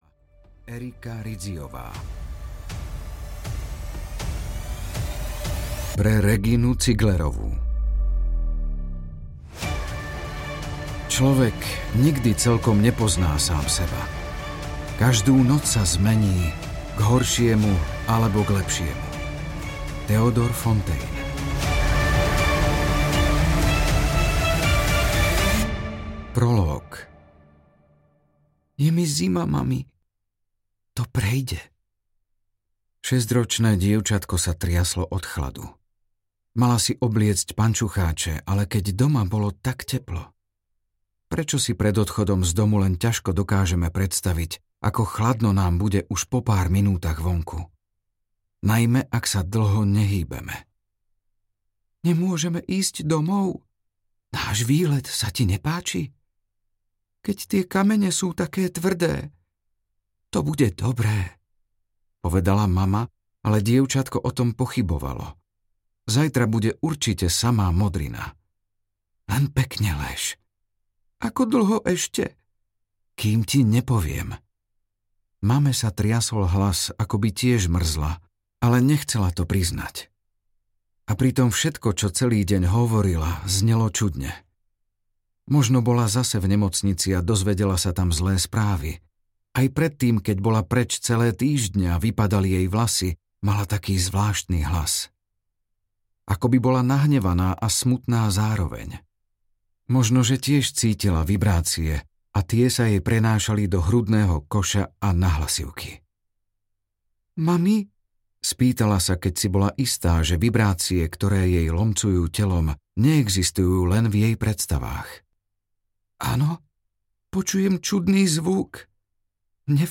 Mimika audiokniha
Ukázka z knihy